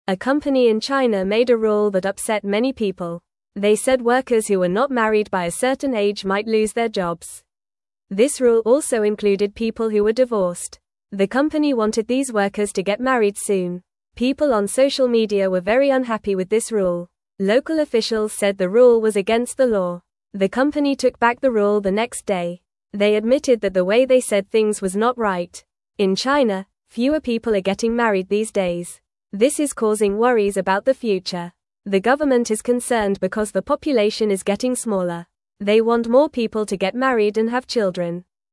Fast
English-Newsroom-Lower-Intermediate-FAST-Reading-Company-Makes-Workers-Marry-or-Lose-Their-Jobs.mp3